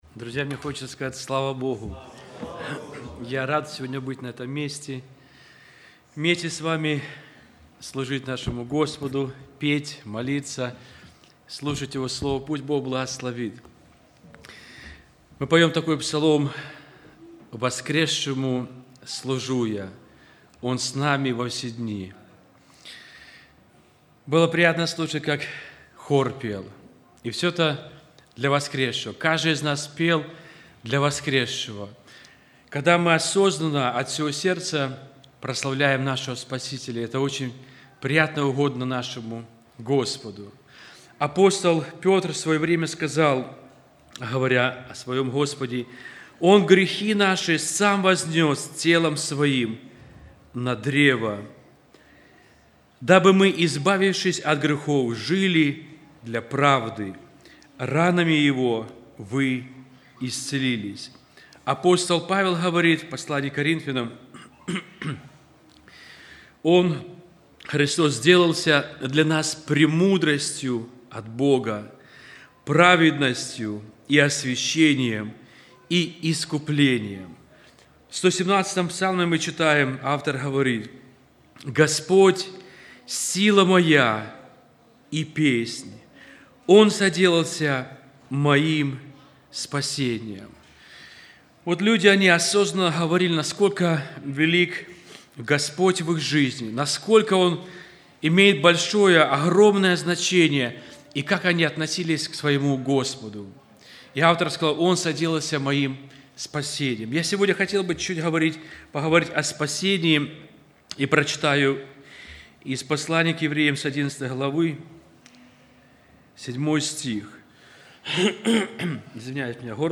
08+Проповедь.mp3